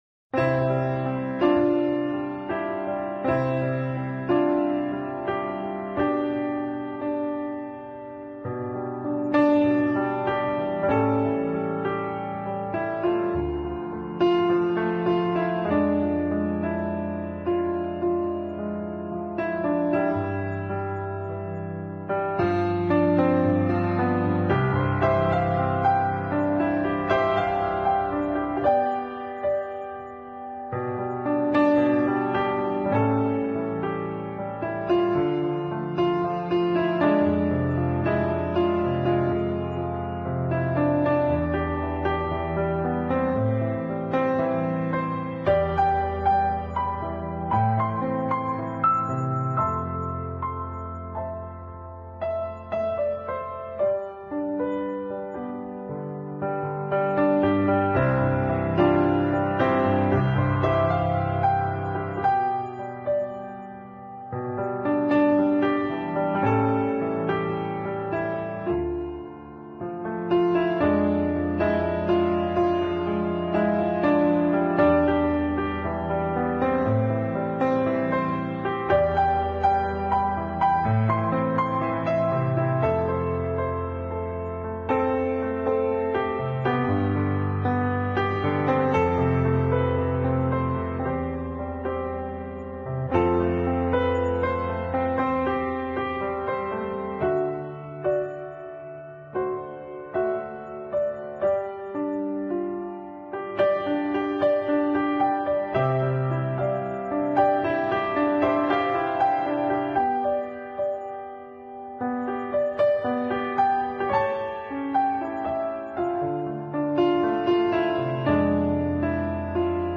【钢琴专辑】
歌曲类型：New Age